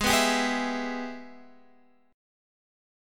AbmM7#5 chord